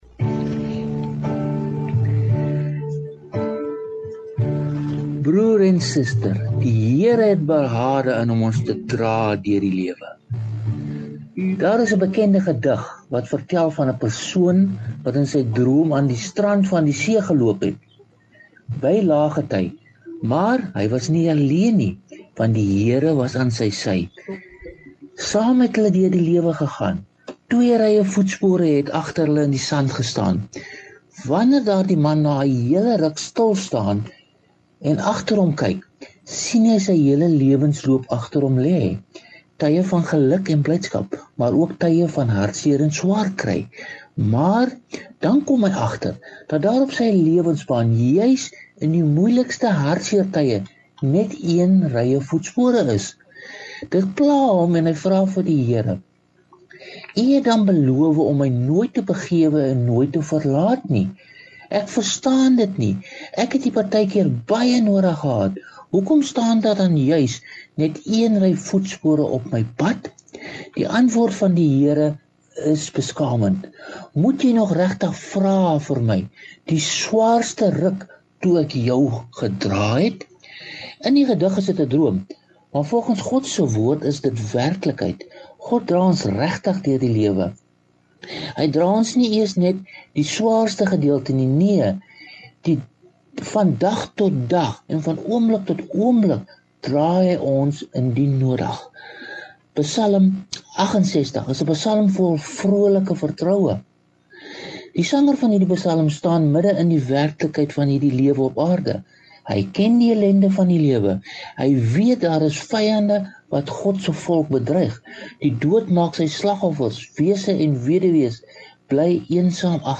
getuienis